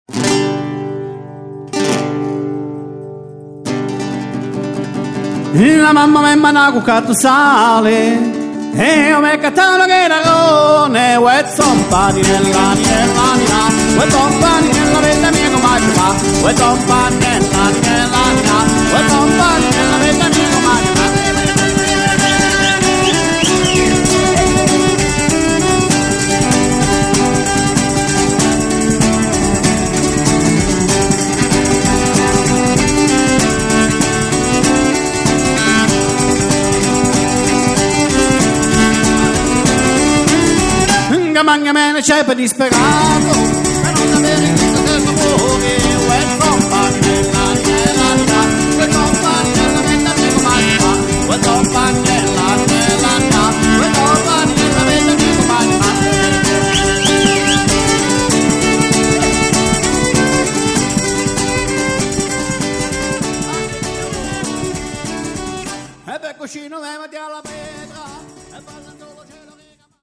Chitarra, Organetto e Voce
Tamburi a cornice, percussioni e danze